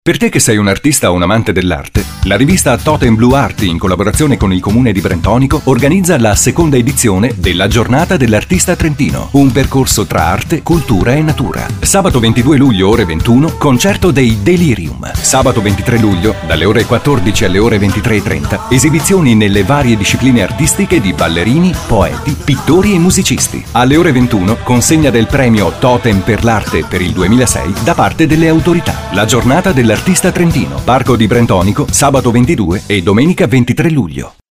SPOT Radiofonico